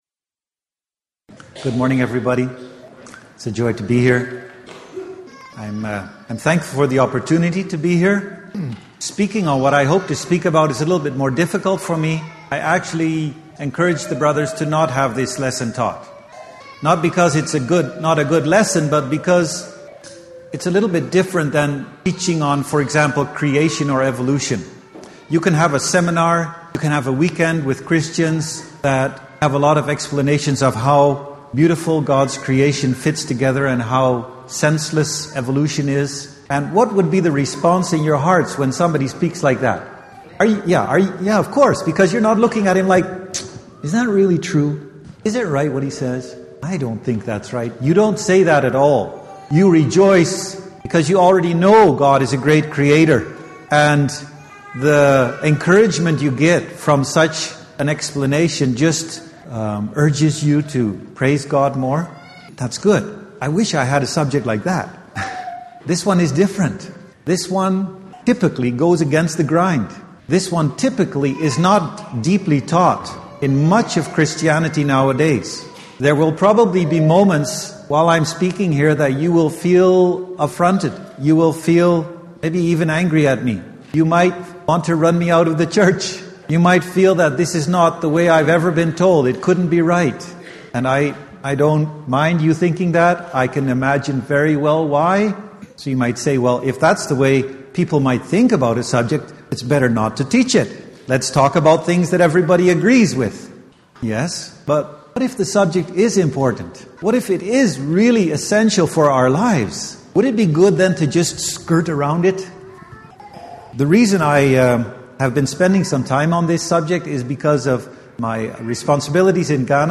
Biblical Finances Seminar Service Type: Sunday Morning %todo_render% « Spiritual Warfare